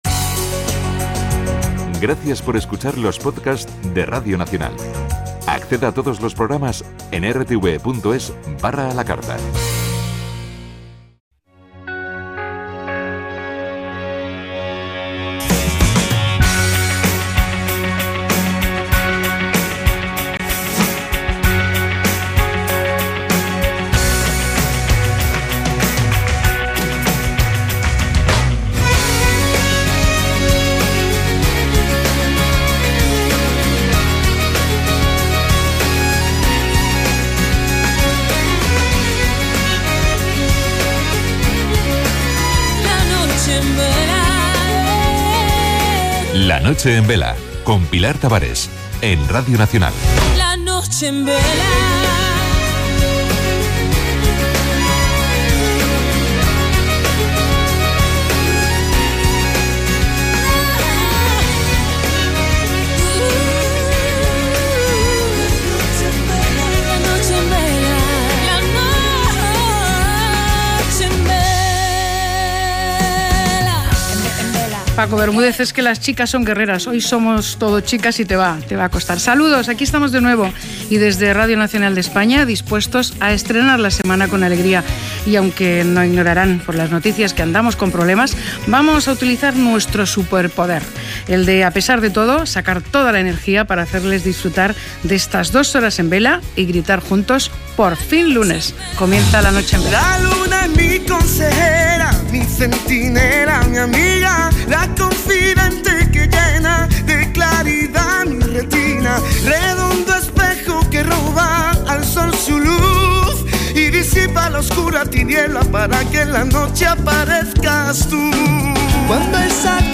Tertulias sobre «Amor del Bueno».
Aquí os dejo el podcast, en el que también intervinieron los oyentes: